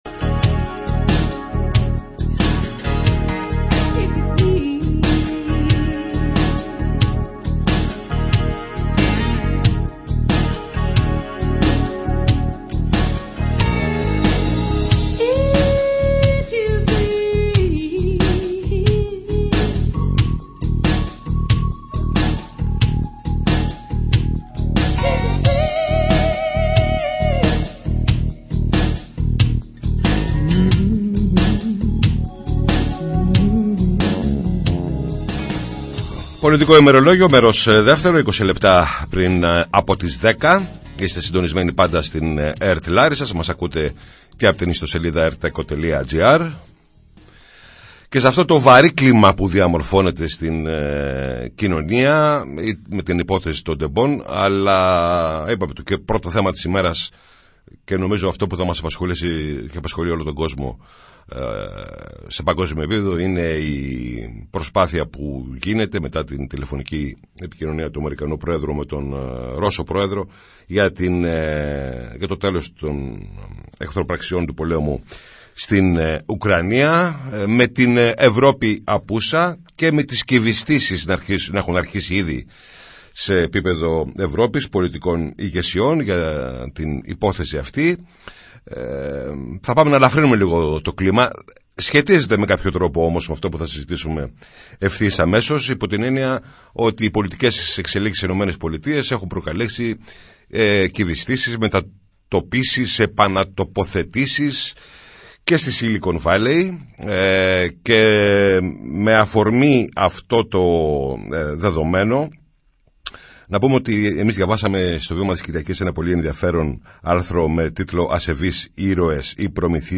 μίλησε στην εκπομπή «Πολιτικό Ημερολόγιο» της ΕΡΤ Λάρισας